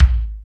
26.02 KICK.wav